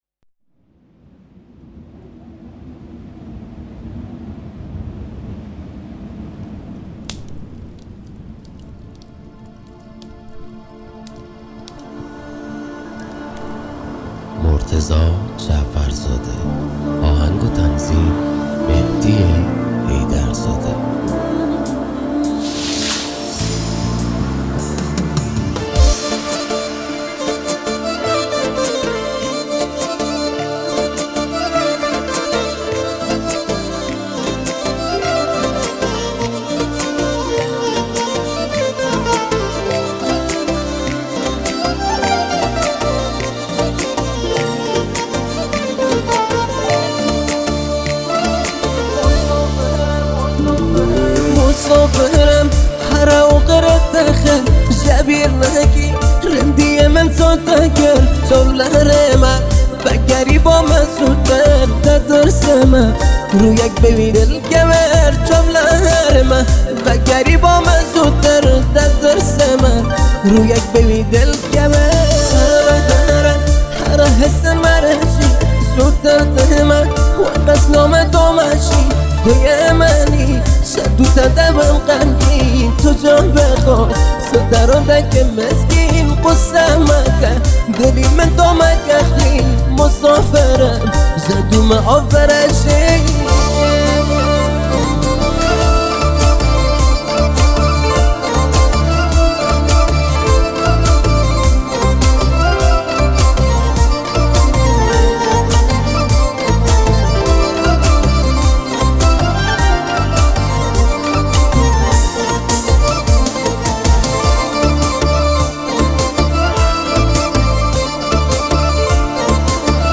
آهنگ محلی کرمانجی خراسانی